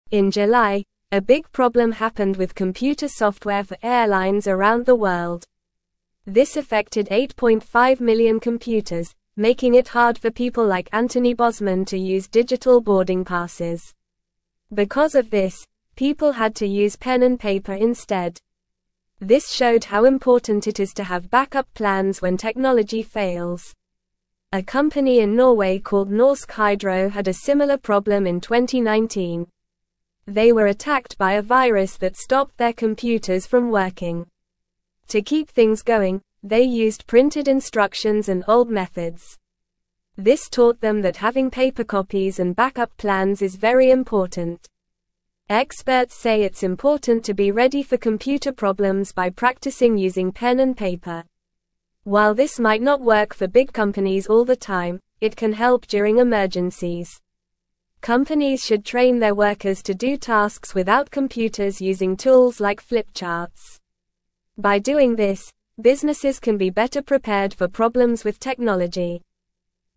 English-Newsroom-Lower-Intermediate-NORMAL-Reading-Paper-is-important-when-computers-dont-work.mp3